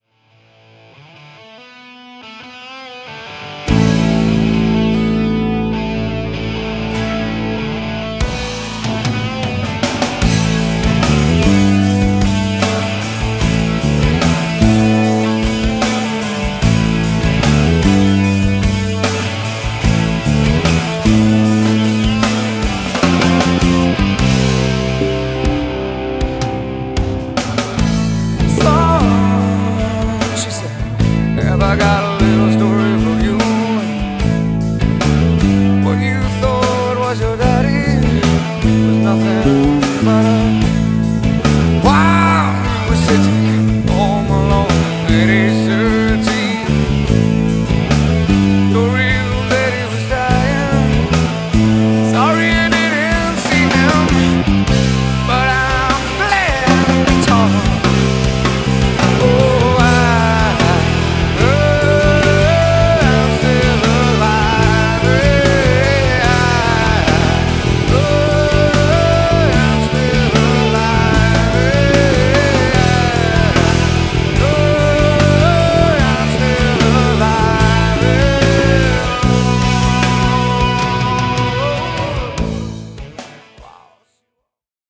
Fretless Home (Bundlose Bässe)
Erste Versuche clean aufn No Bass Track gespielt.